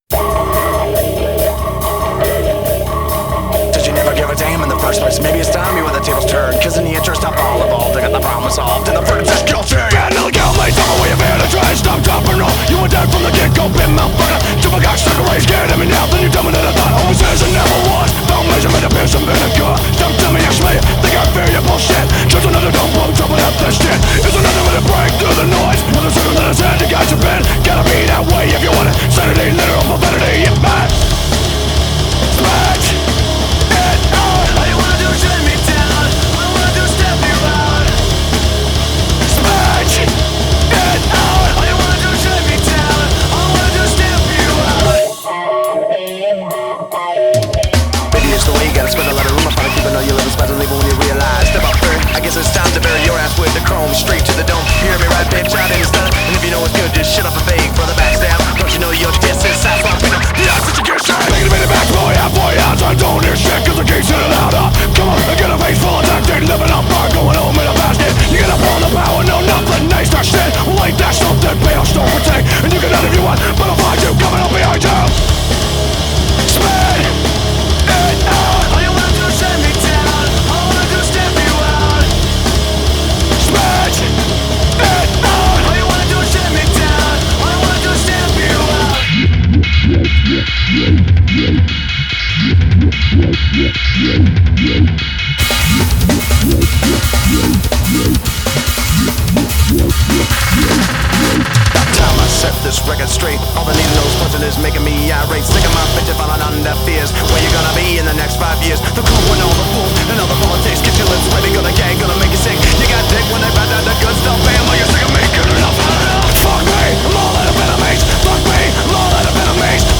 2025-01-03 17:27:32 Gênero: Rock Views